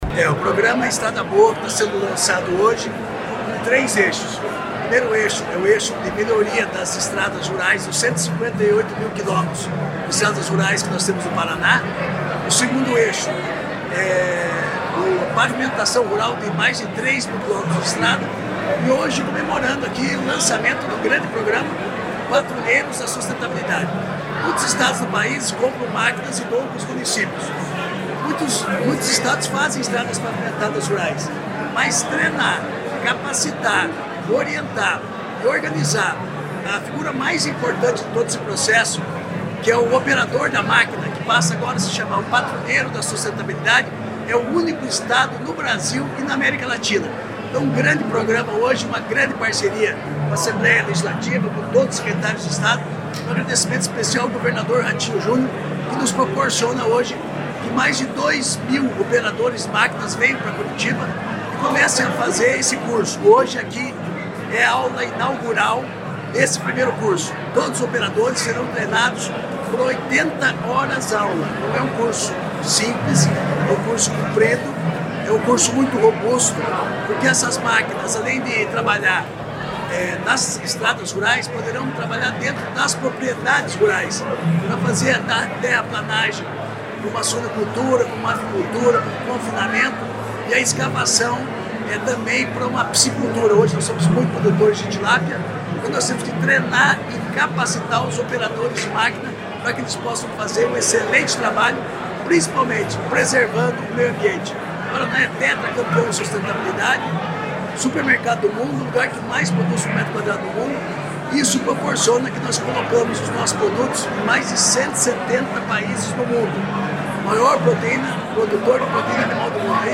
Sonora do secretário da Agricultura e do Abastecimento, Márcio Nunes, sobre o programa Patrulheiros da Sustentabilidade